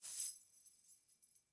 抖动链条
描述：这是一个盘旋高尔夫球洞的链条的记录。
标签： 咔嗒咔嗒 发抖